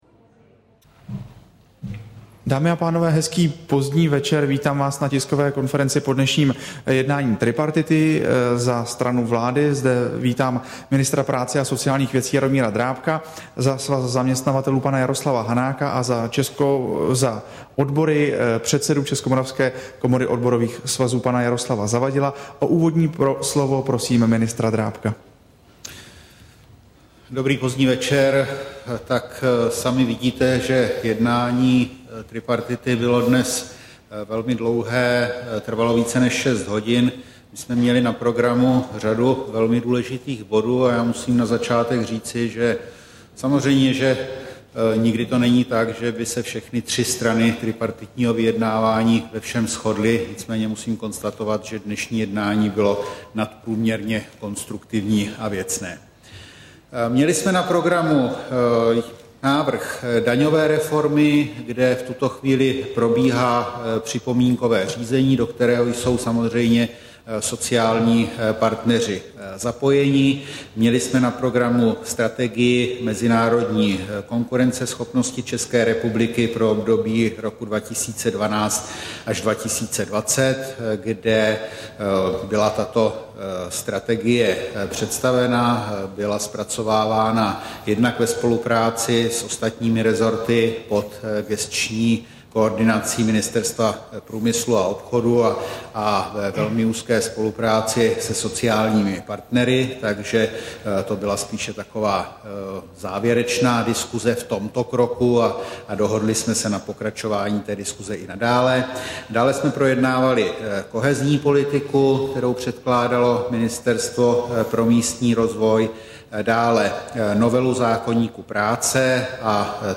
Tisková konference po jednání tripartity, 20. června 2011